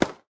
pew.ogg